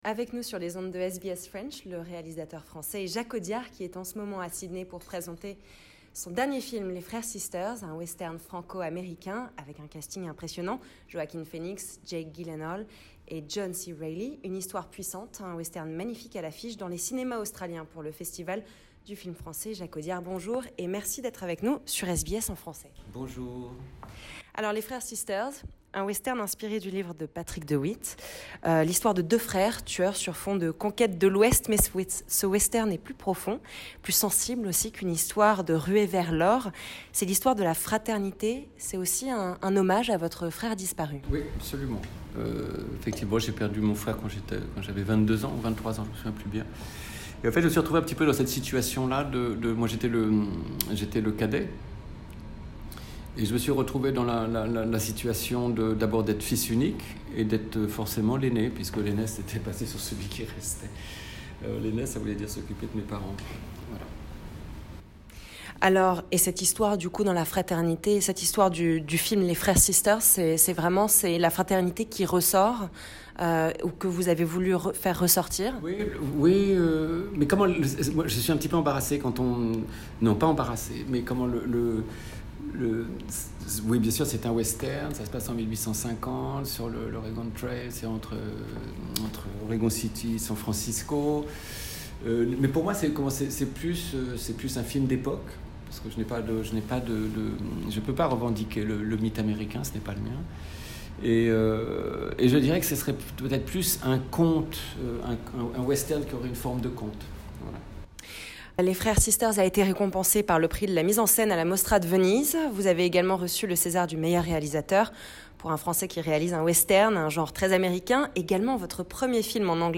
Le réalisateur français Jacques Audiard est sur les ondes de SBS French pour nous parler de son dernier film, Les Frères Sisters ou The Sisters Brothers. Un Western franco-américain salué par la critique.